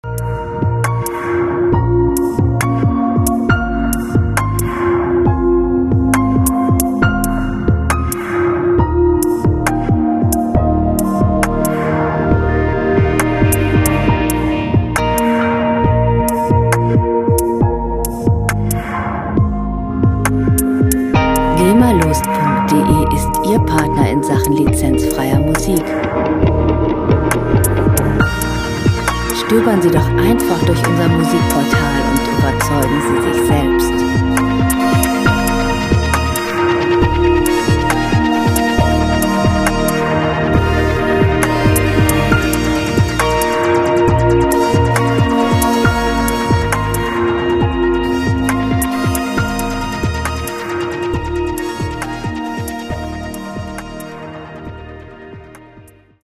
Elektronische Musik - Moderne Welt
Musikstil: New Age
Tempo: 68 bpm
Tonart: B-Moll
Charakter: ausgefallen, effektvoll
Instrumentierung: Synthesizer, Gitarre